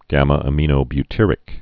(gămə-ə-mēnō-by-tîrĭk, -ămə-)